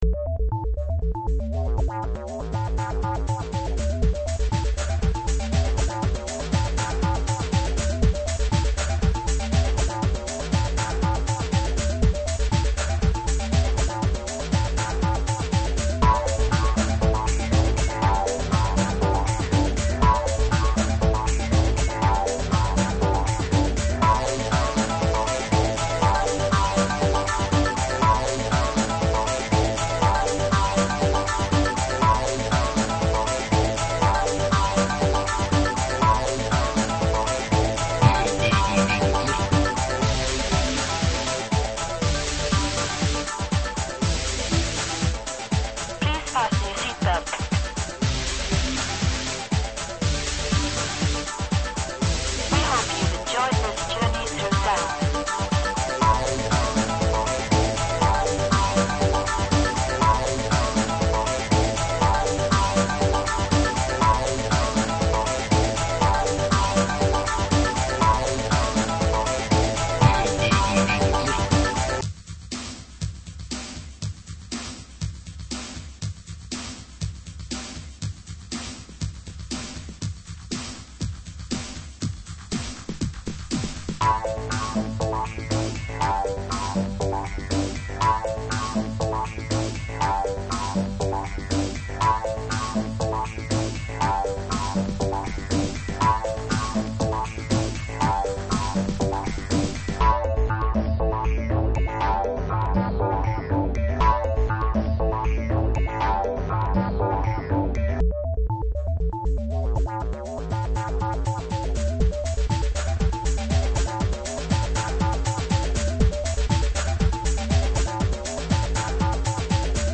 ただ、普通「作曲」というと音符を並べて作ることを想像すると思いますが、これは Loop-Based、つまり誰かが作った部品（ループ）を組み合わせて作曲するもので、細かい制御こそできませんが、「それなり」の作品を短時間で制作することができます（自動作曲ソフトではありません）。